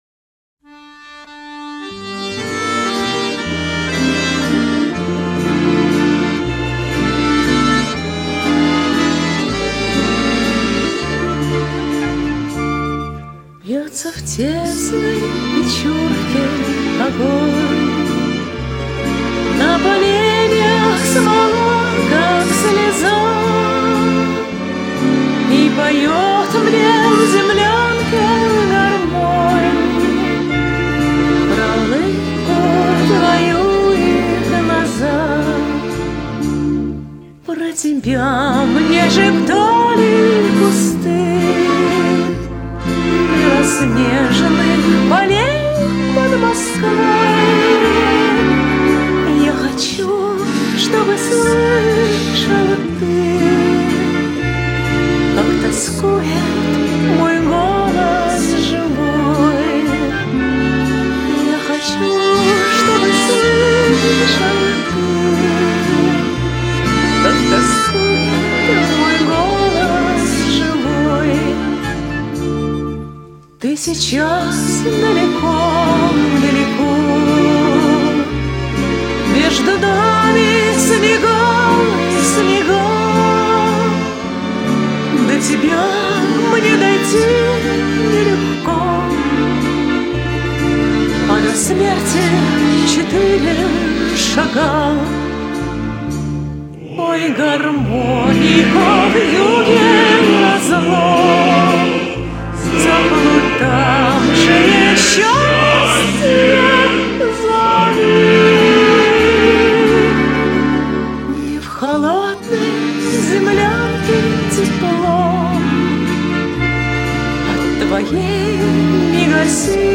А партии одни и те же, что основные, что в подпевках. pivo